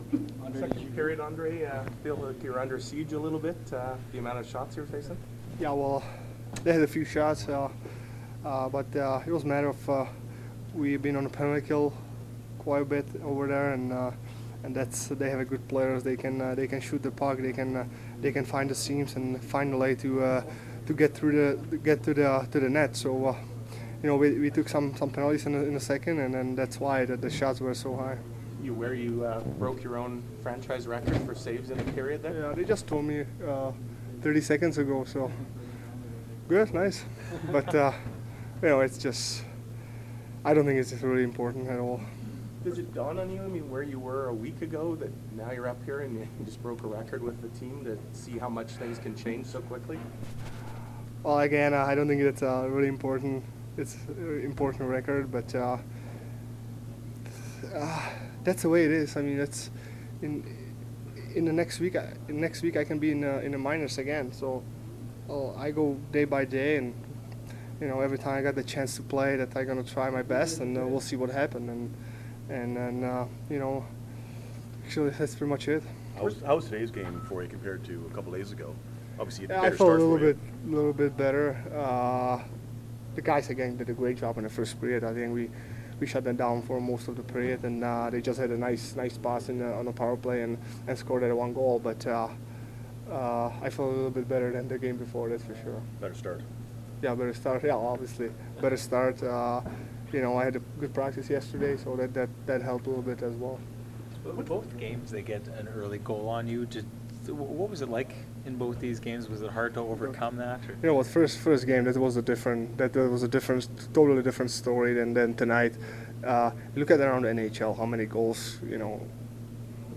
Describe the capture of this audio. Post-game from the Jets and Blues dressing rooms as well as from Coach Maurice and Coach Hitchcock.